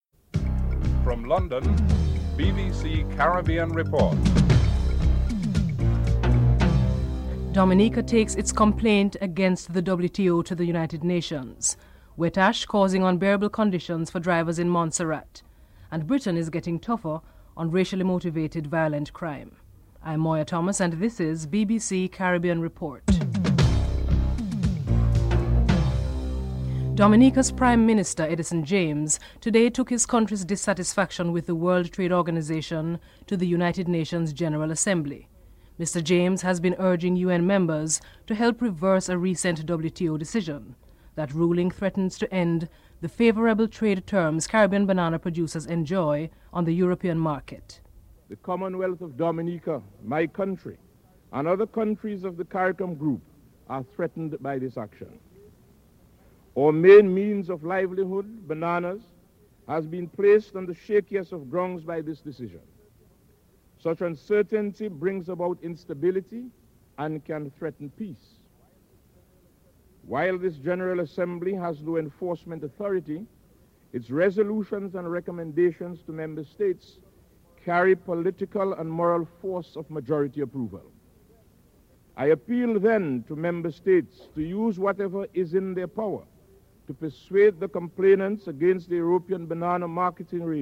1. Headlines (00:00-00:25)